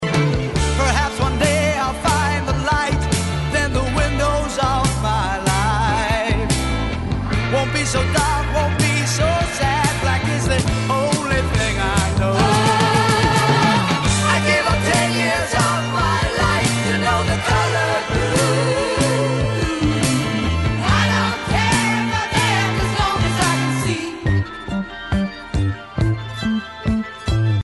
at Trident Studios, London